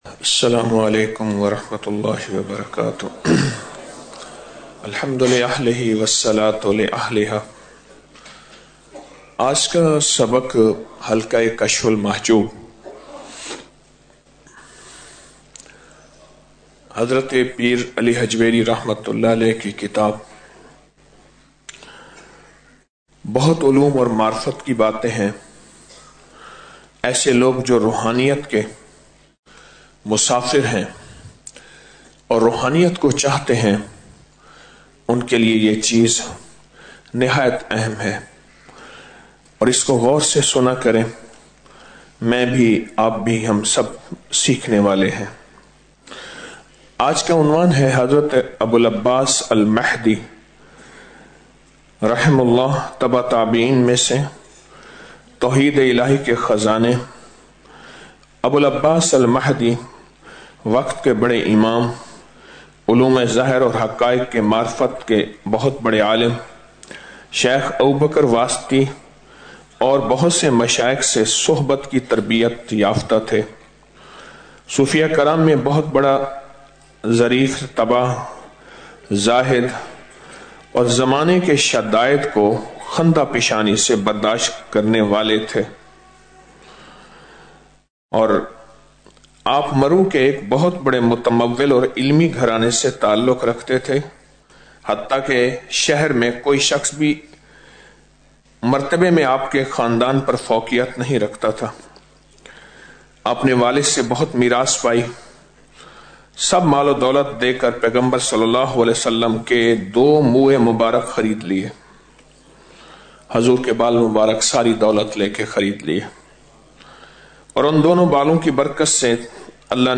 Audio Speech - 02 Ramadan After Salat Ul Fajar - 03 March 2025